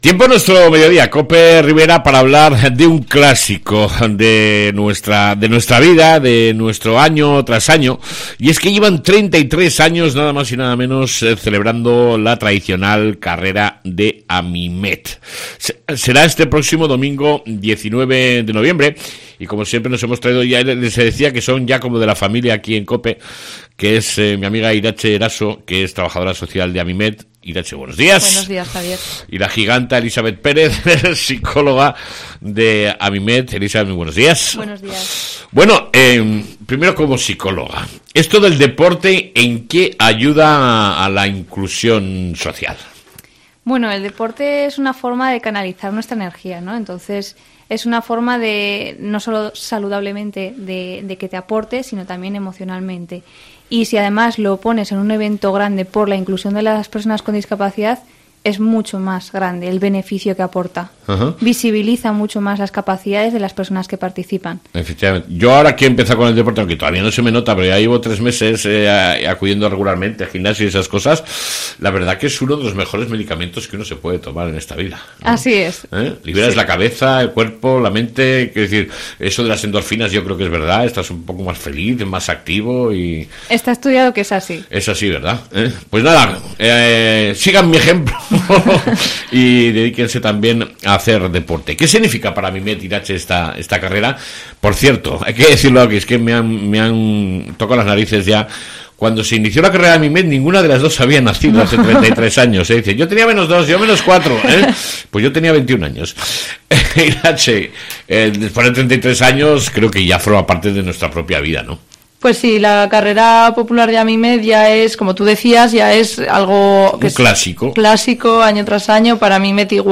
ENTREVISTA CON AMIMET